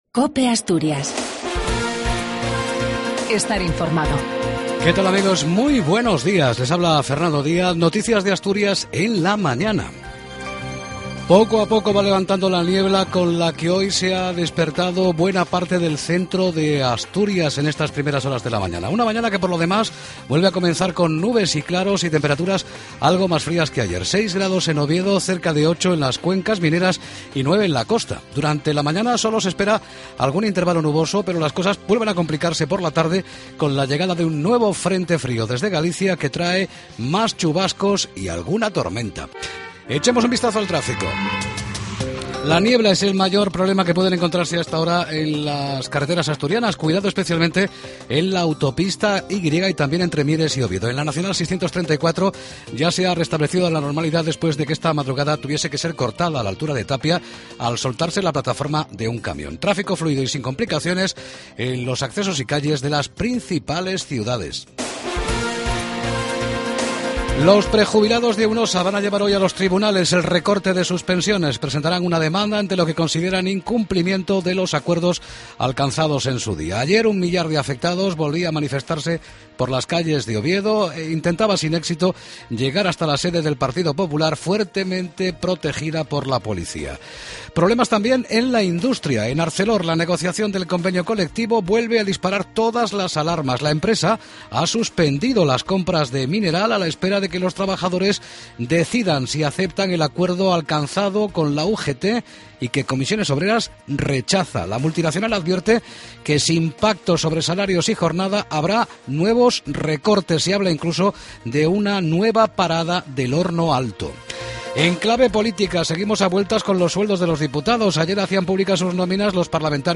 La Mañana Informativos